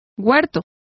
Complete with pronunciation of the translation of garden.